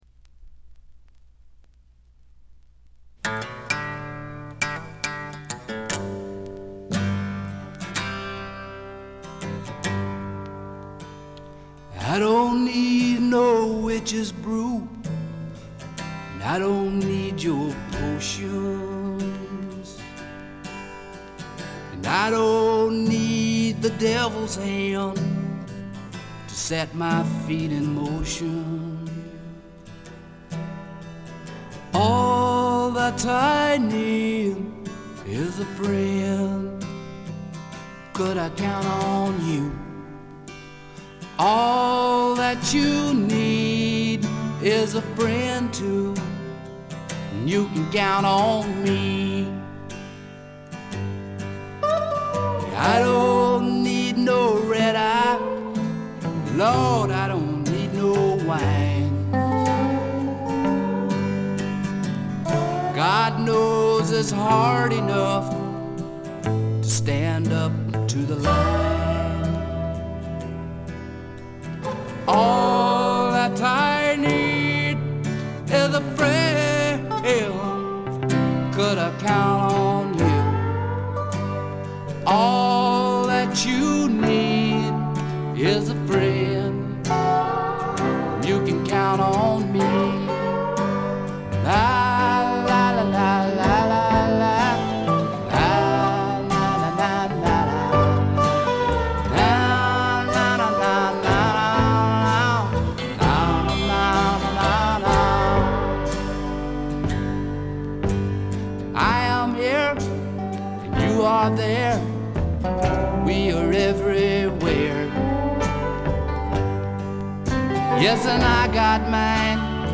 ところどころで軽微なチリプチ。散発的なプツ音少し。
試聴曲は現品からの取り込み音源です。